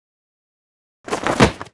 倒地2-YS070510.wav
通用动作/01人物/02普通动作类/倒地2-YS070510.wav
• 声道 單聲道 (1ch)